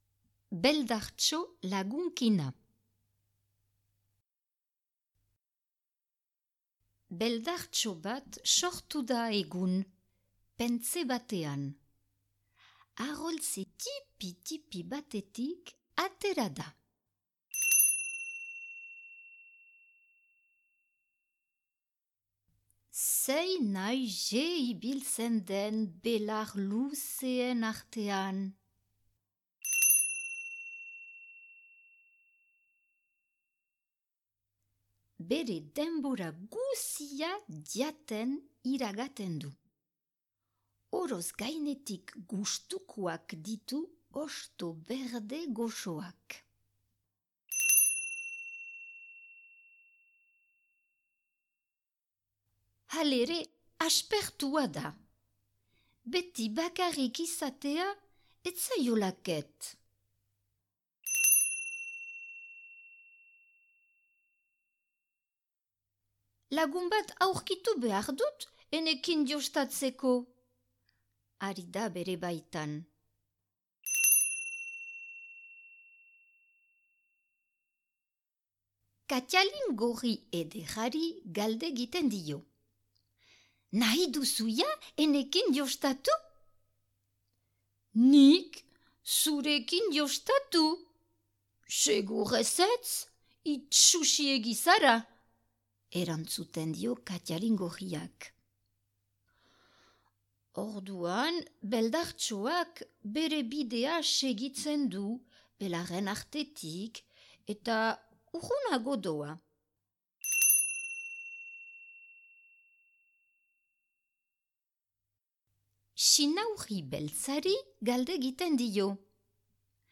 Beldartxo lagunkina - ipuina entzungai